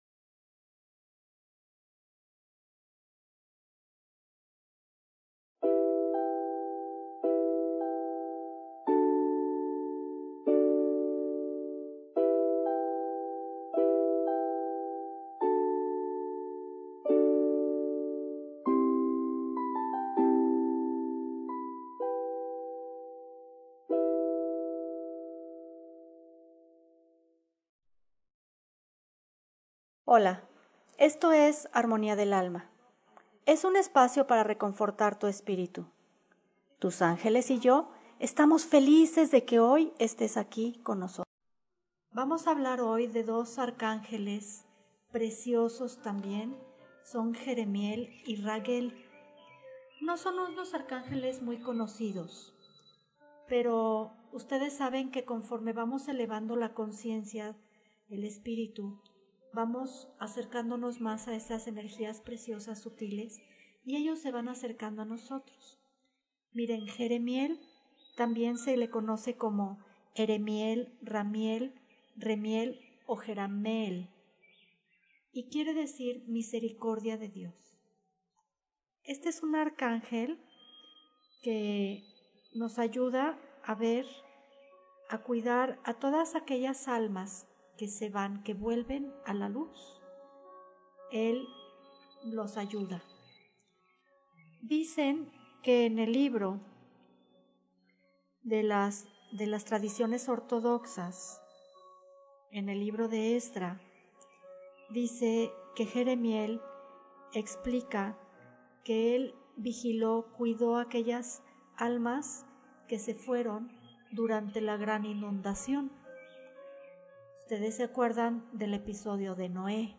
CLASE 14: Arcángeles Jeremiel y Raguel